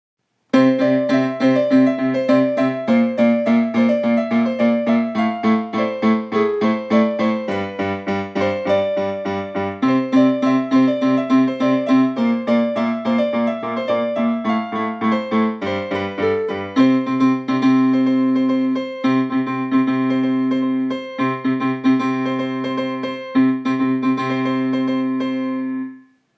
千葉ロッテマリーンズ #24 吉田裕太 応援歌